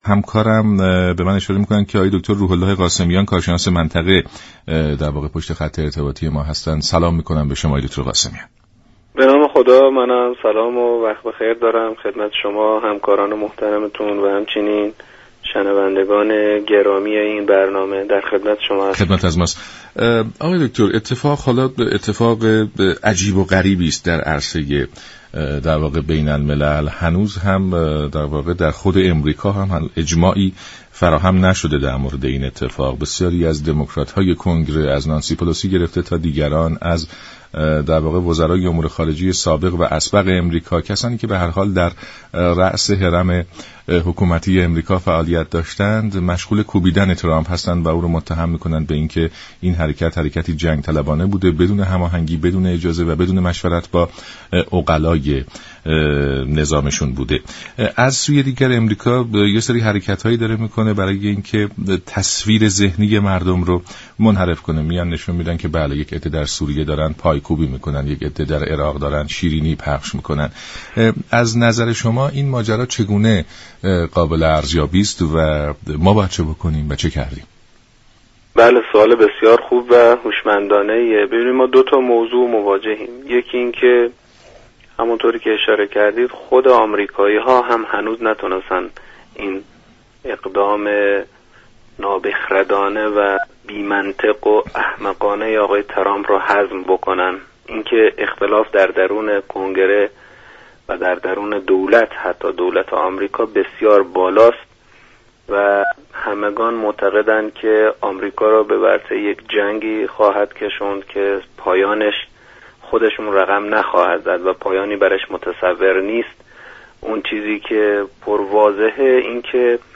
كارشناس مسائل منطقه در بخش دیگر صحبت هایش خاطر نشان كرد: آمریكا با فضاسازی رسانه ای خود و مطرح كردن مباحثی چون جنگ و صلح، اقتصاد دو قطبی و وزن كشی دو جانبه به دنبال مدیریت افكار عمومی است. برنامه «ایران امروز» شنبه تا سه شنبه هر هفته ساعت 12:40 از رادیو ایران پخش می شود.
كارشناس مسائل منطقه